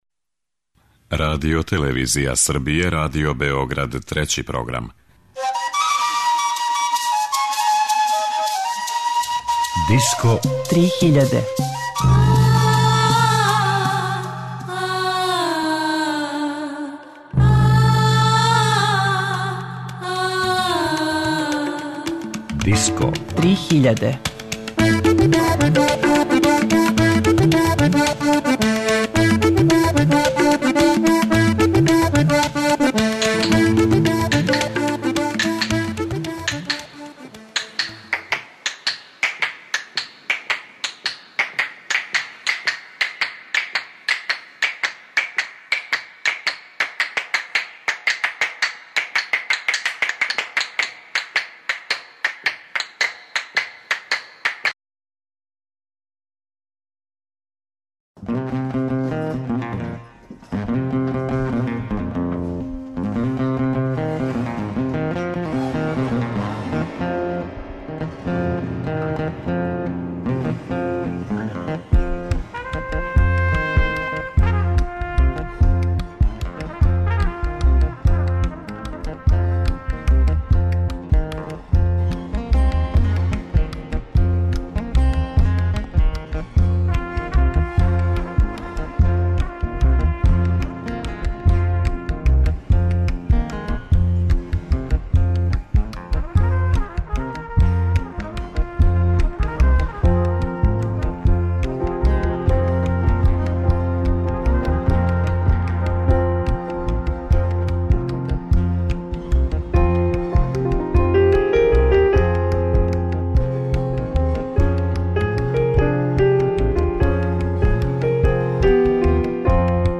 Три жичана инструмента, три континента, троје уметника.Овај пут сви инструменти почињу на слово К. Слушаћете фински кантеле, ирански кеменче и афричку кору.